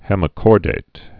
(hĕmĭ-kôrdāt, -dĭt)